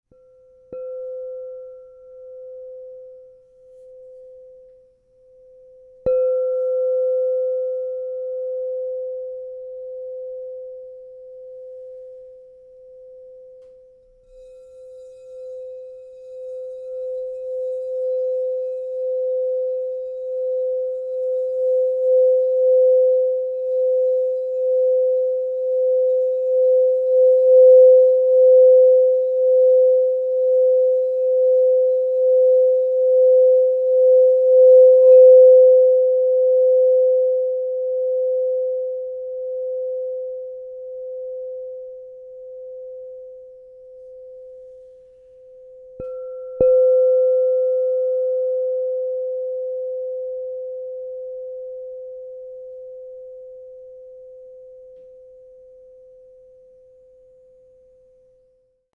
Tanzanite, Palladium 6" C -35 Crystal Tones Singing Bowl
At 6 inches in size, tuned to the note of C -35, this bowl resonates with the root chakra, offering grounding, stability, and a secure foundation for spiritual growth. Its compact size produces focused, crystalline tones that are both intimate and commanding—ideal for personal meditation, ceremony, and sacred space activation.
432Hz (-)